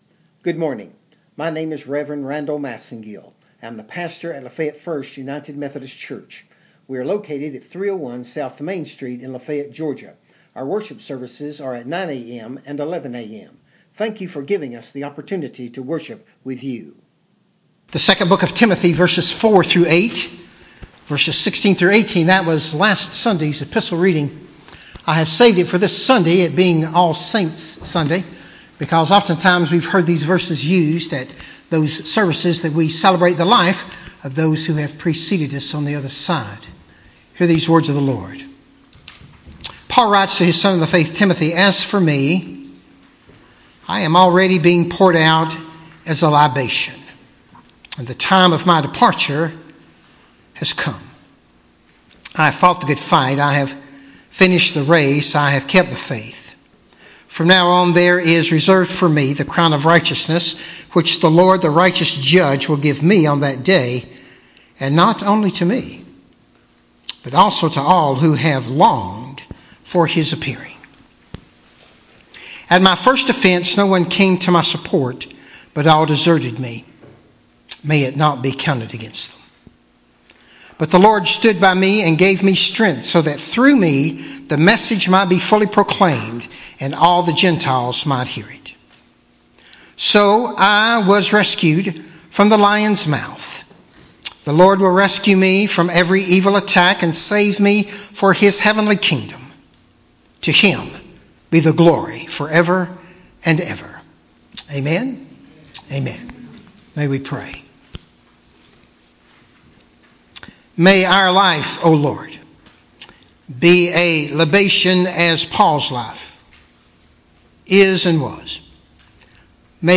11-3-19-sermon-website-CD.mp3